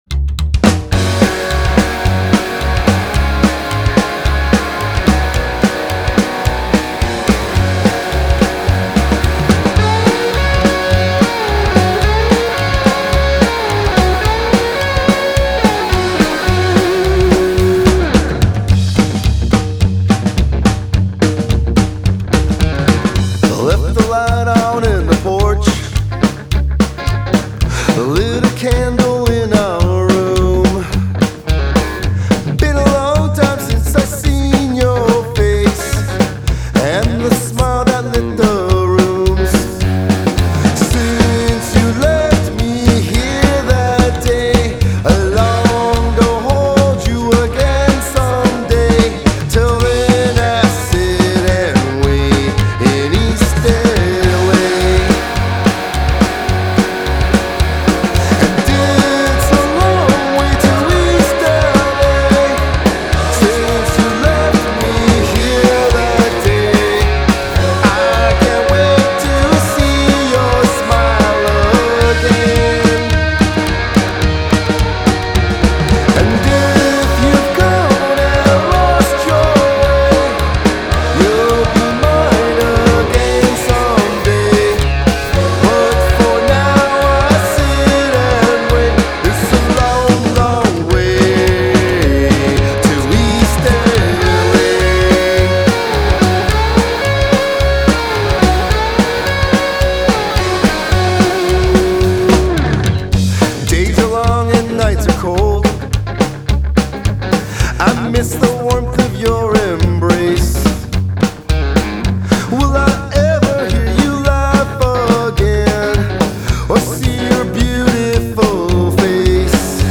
Ready to rock the house!!